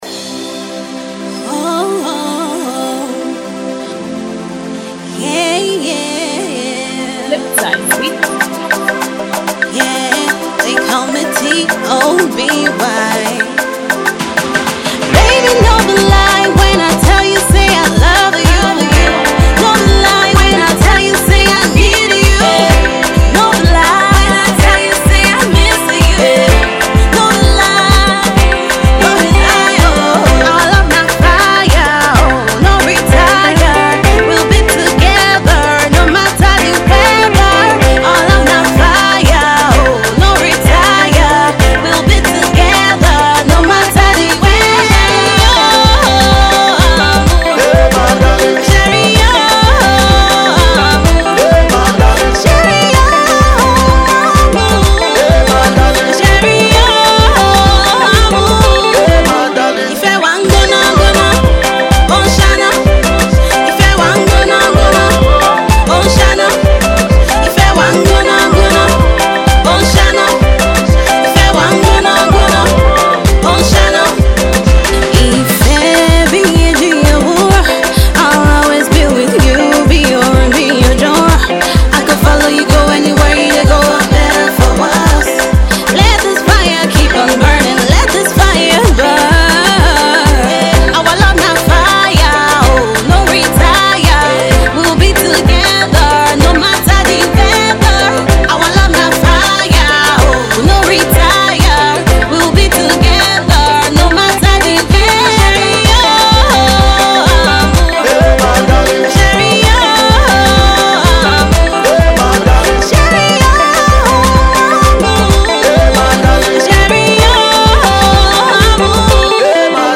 guitar
is a soft sound and we’ve had it on repeat all morning.